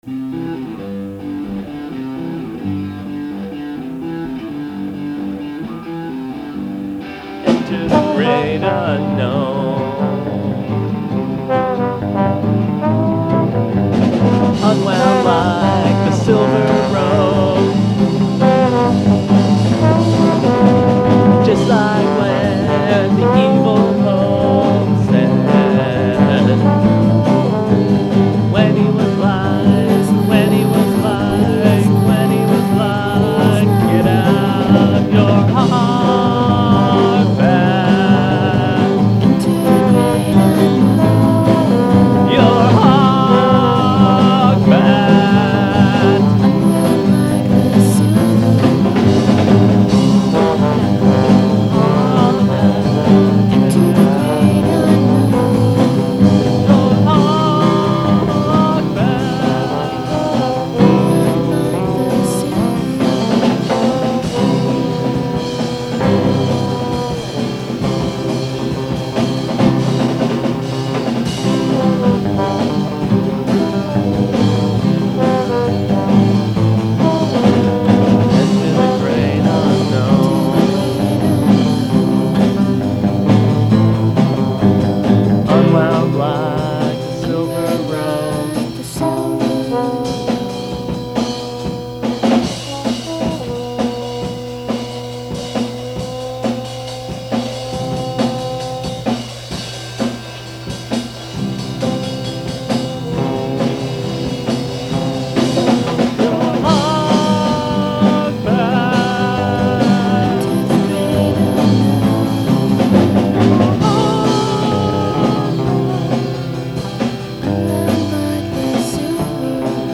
trombone
shambolically perfect drums
enthusiastic vocals and jittery guitar chords
frenzied lead guitar
serenely melodic singing